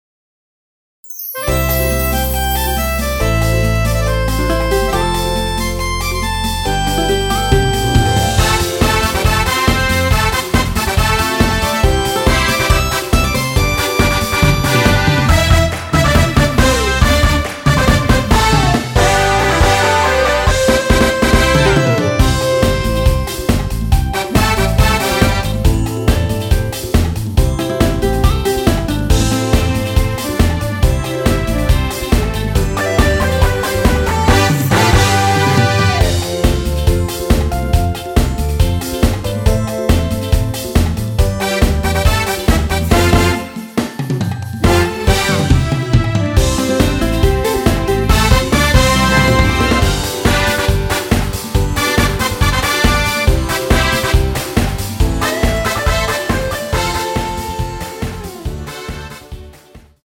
원키 코러스 포함된 MR 입니다.
앞부분30초, 뒷부분30초씩 편집해서 올려 드리고 있습니다.
중간에 음이 끈어지고 다시 나오는 이유는